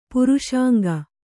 ♪ puruṣāŋga